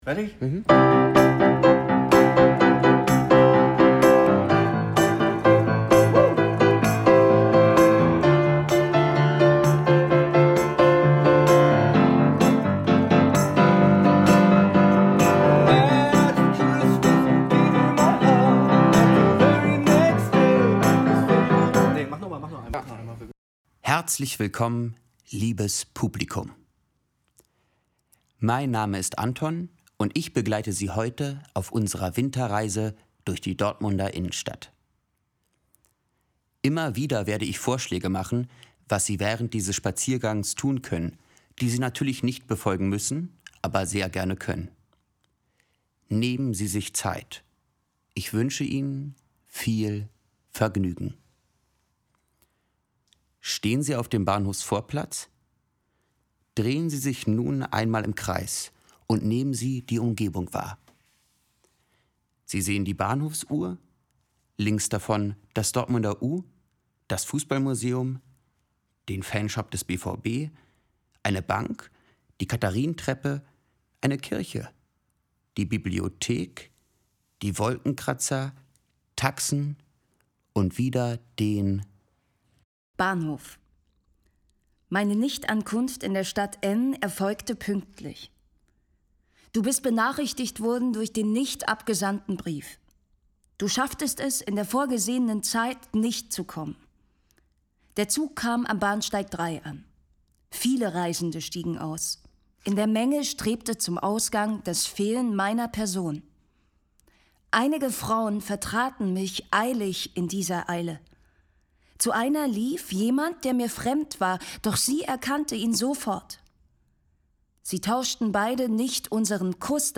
Die Schauspieler*innen des Schauspiel Dortmund gehen mit Ihnen spazieren – quer durch die Innenstadt, vom Hauptbahnhof über die Reinoldikirche bis zum Hansaplatz.